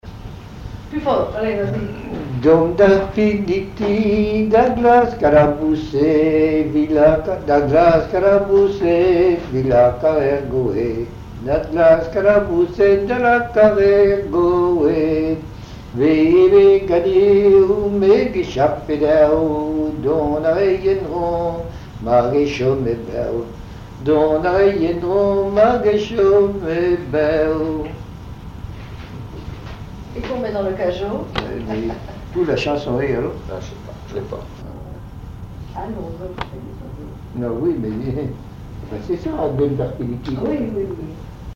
Mémoires et Patrimoines vivants - RaddO est une base de données d'archives iconographiques et sonores.
Genre strophique
Chansons en breton
Pièce musicale inédite